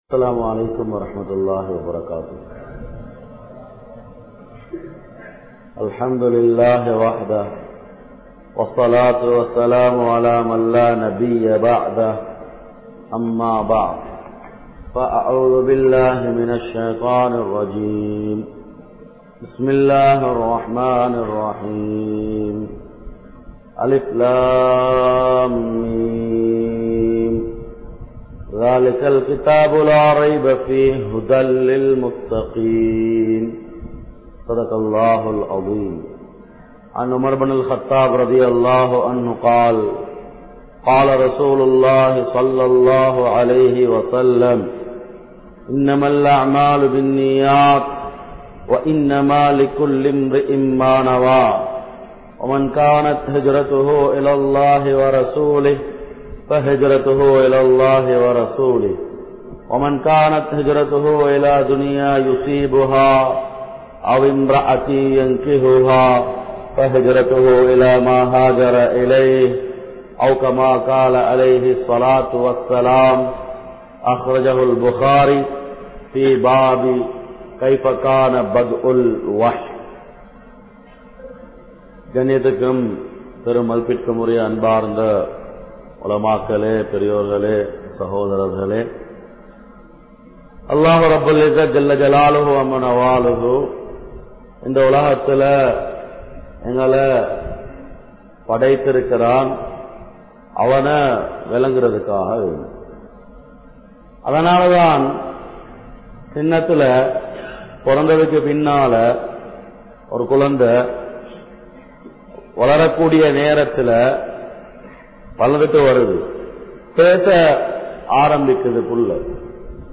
Eemanudaiya Ulaippu (ஈமானுடைய உழைப்பு) | Audio Bayans | All Ceylon Muslim Youth Community | Addalaichenai